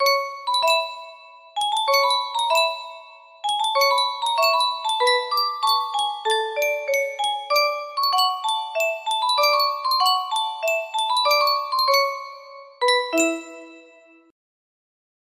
Yunsheng Music Box - Chopin Polonaise Op. 53 Y485 music box melody
Full range 60